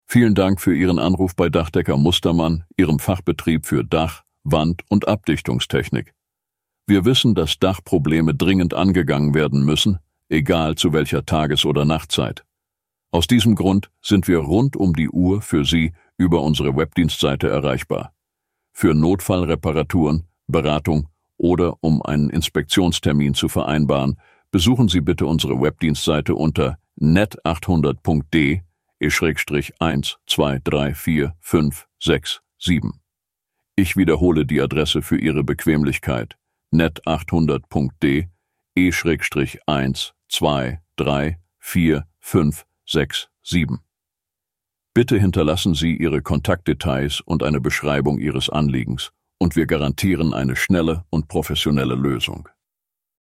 Hören Sie hier eine Beispiel-Anrufbeantworteransage.
Dachdecker.mp3